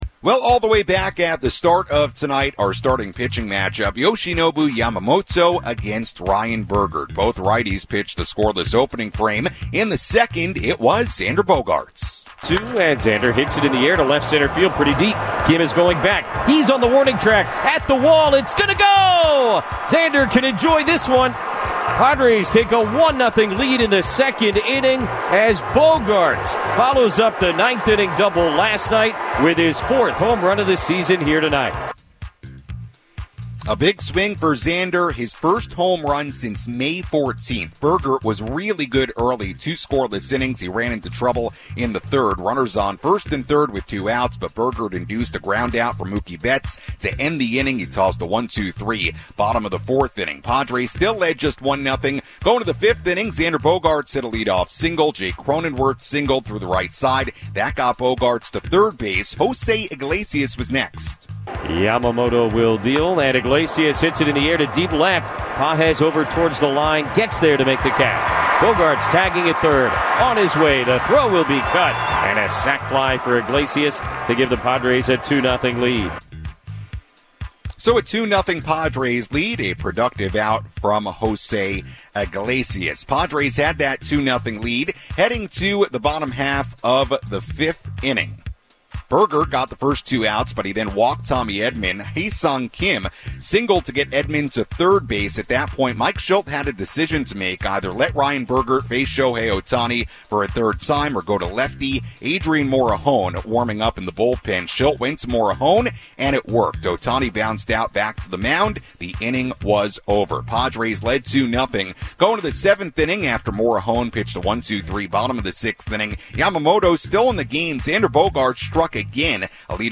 along with play-by-play highlights.